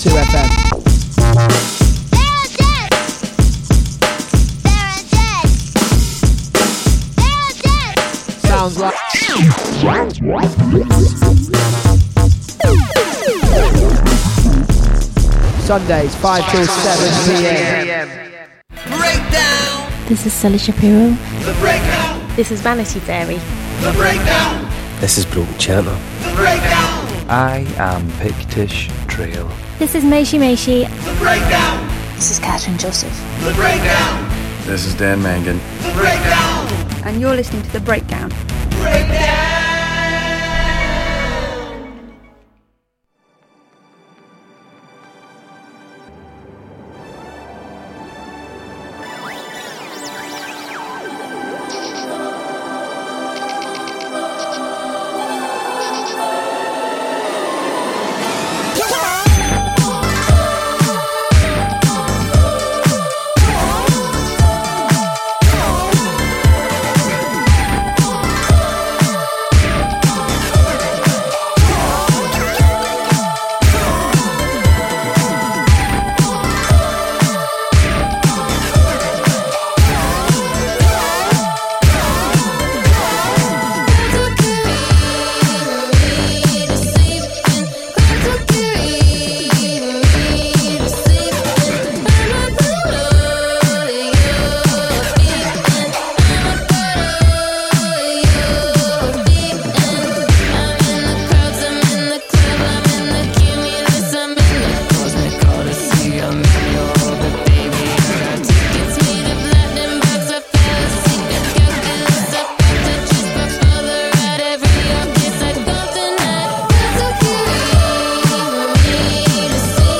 A weekly musical jamboree transmitting live to the world every Saturday afternoon.